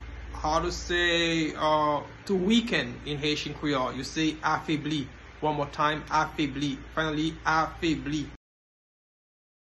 Listen to and watch “Afebli” pronunciation in Haitian Creole by a native Haitian  in the video below:
Weaken-in-Haitian-Creole-Afebli-pronunciation-by-a-Haitian-teacher.mp3